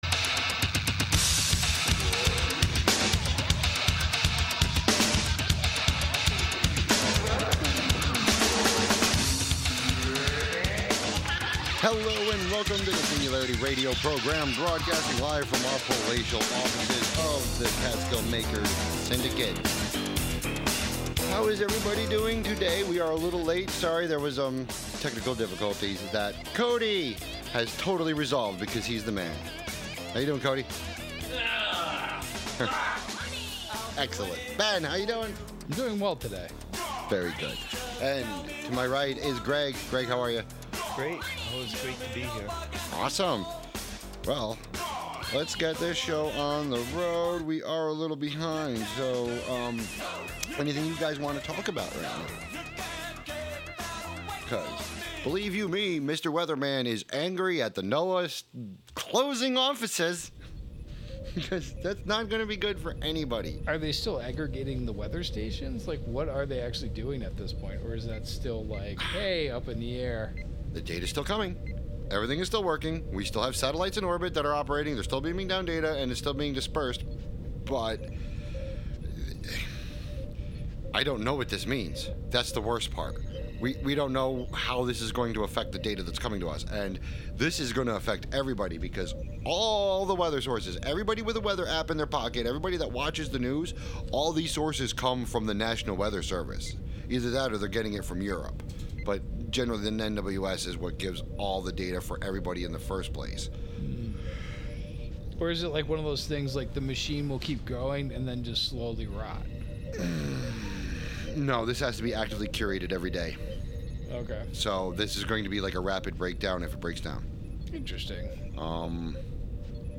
Live from the Thingularity Studios: Thingularity (Audio)